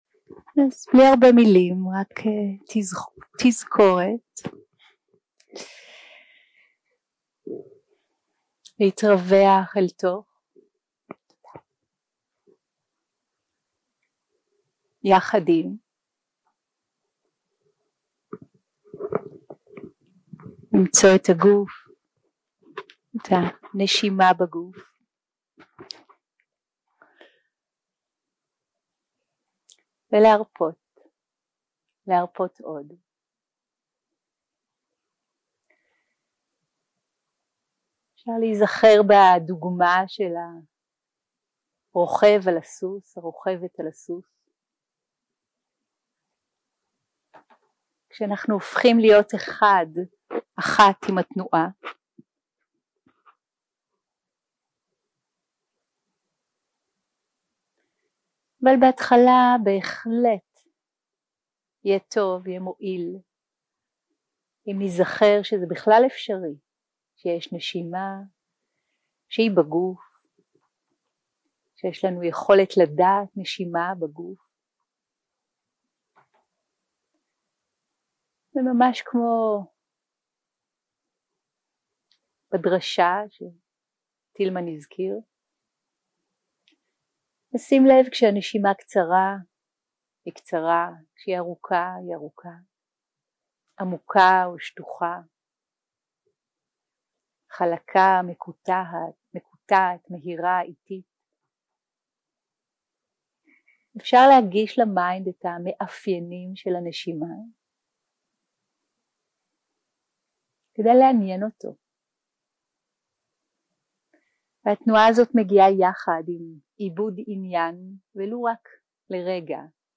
Dharma type: Guided meditation שפת ההקלטה